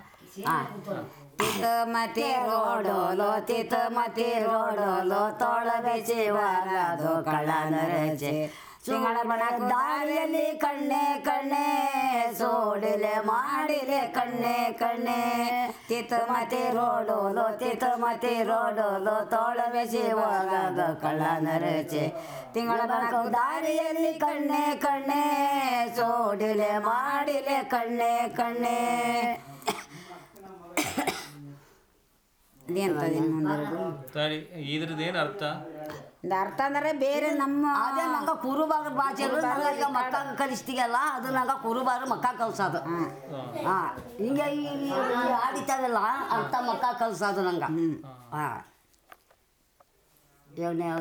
Performance of Jenu Kuruba tribe folk song